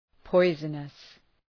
Προφορά
{‘pɔızənəs}